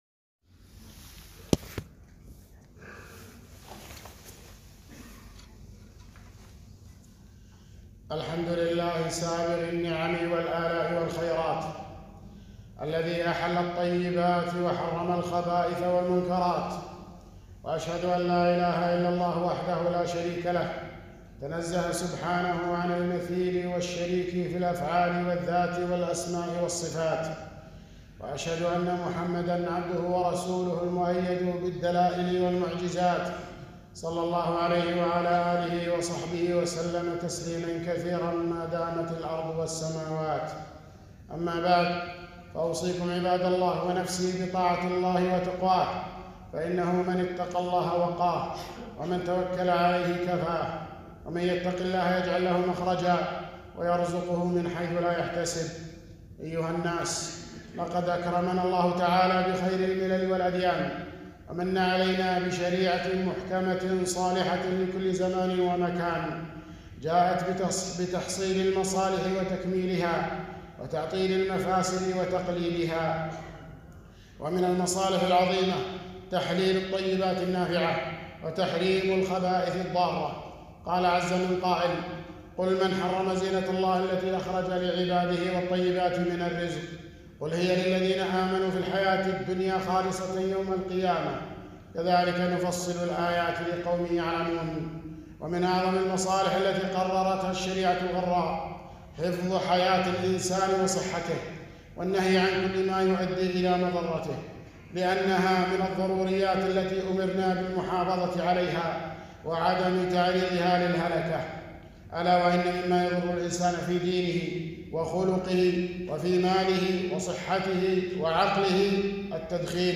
خطبة - آفة التدخين